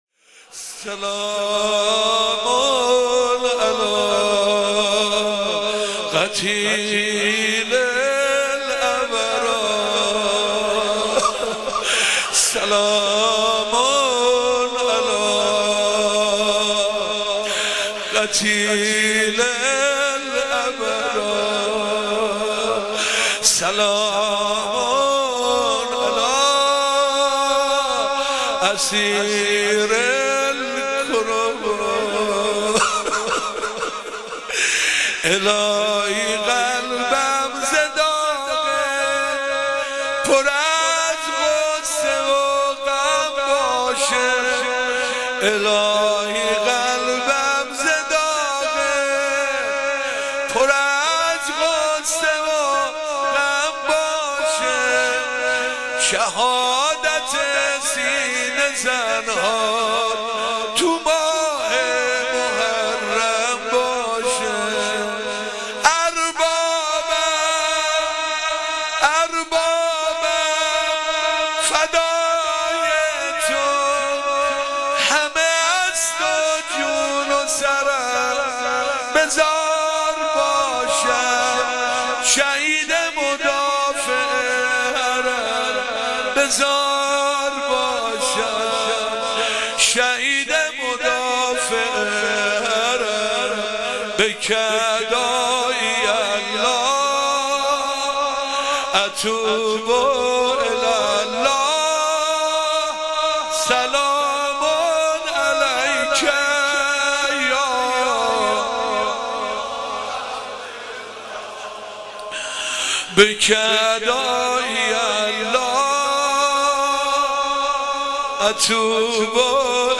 مناسبت : شب پنجم محرم
مداح : حسین سازور قالب : زمینه